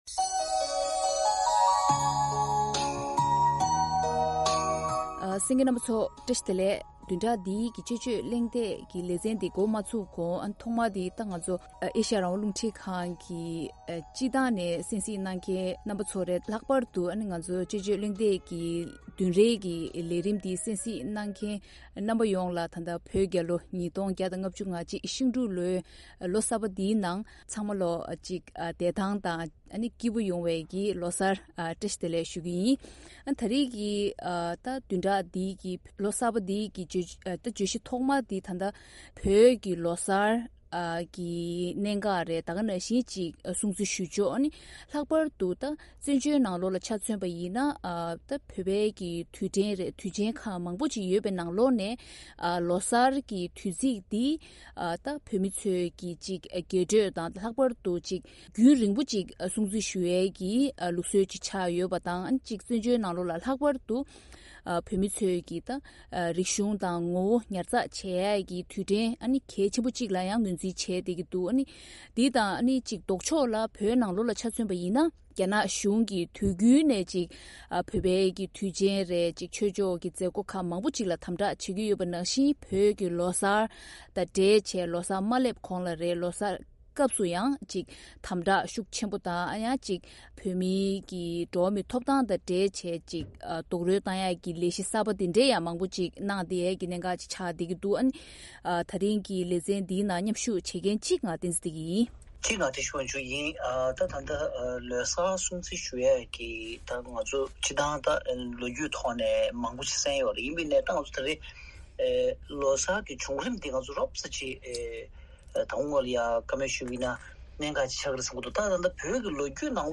ཐེངས་འདིའི་དཔྱད་བརྗོད་གླེང་སྟེགས་ཀྱི་ལས་རིམ་ནང་།